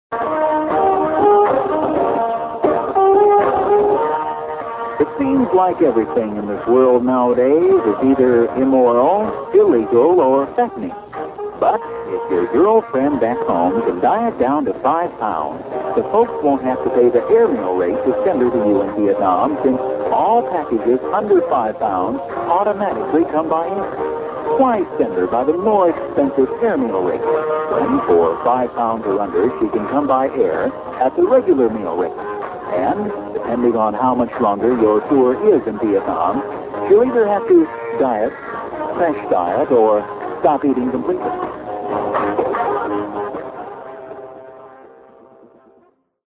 Airmail PSA